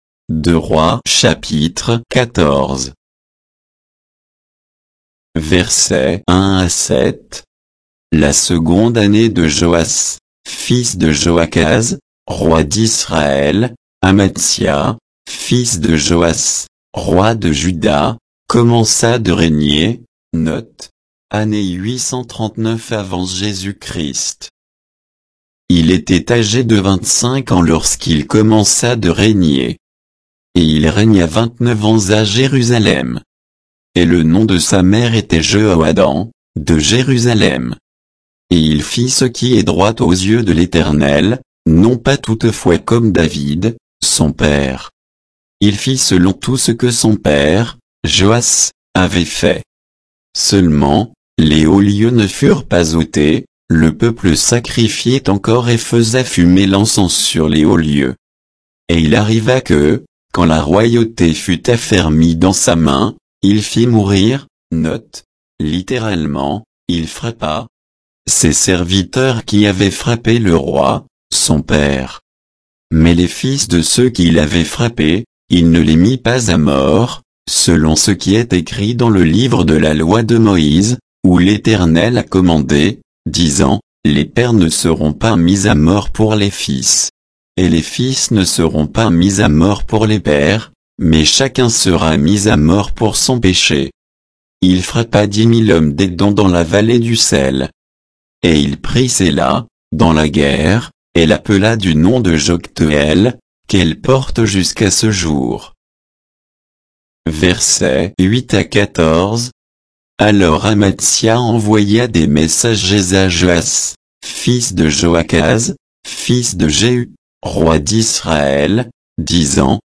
Bible_2_Rois_14_(avec_notes_et_indications_de_versets).mp3